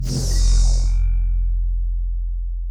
pgs/Assets/Audio/Sci-Fi Sounds/Electric/Shield Device 2 Stop.wav at master
Shield Device 2 Stop.wav